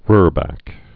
(rrbăk)